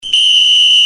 shaosheng.mp3